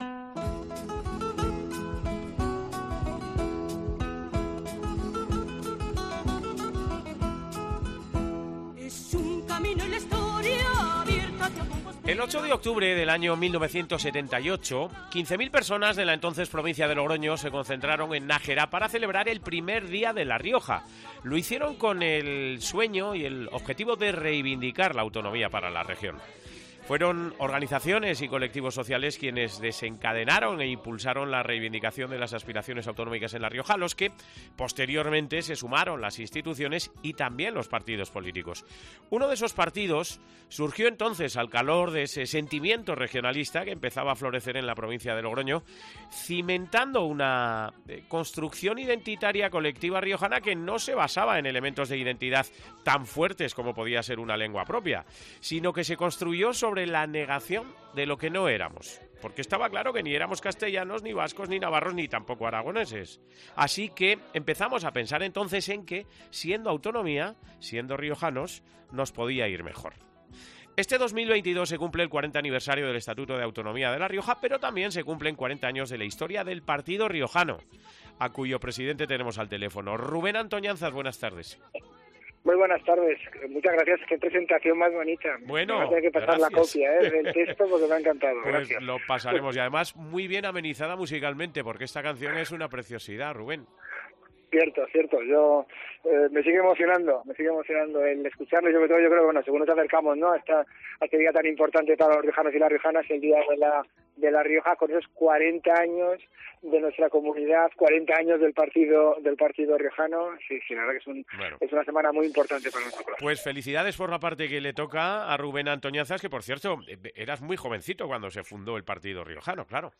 El presidente del Partido Riojano ha subrayado hoy lunes, 6 de junio, en COPE Rioja, la importancia del 40 aniversario del Estatuto de Autonomía.